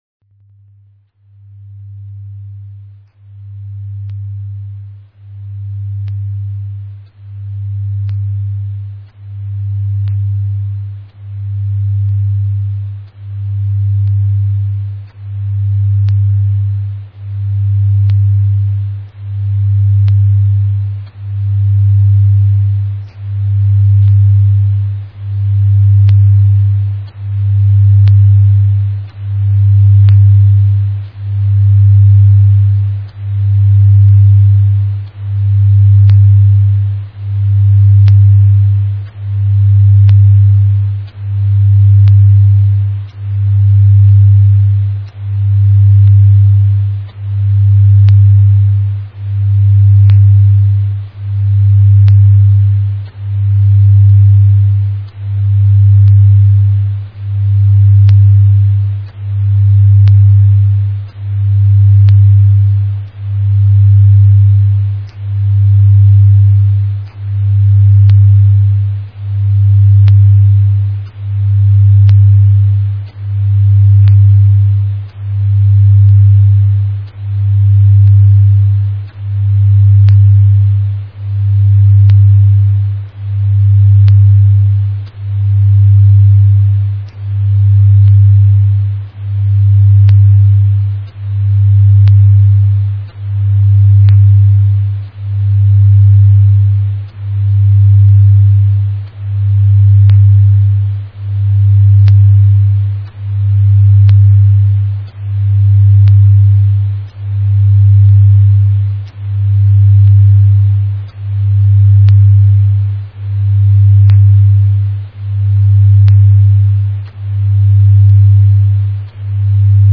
Цифровой наркотик (аудио наркотик) Прикосновение Бога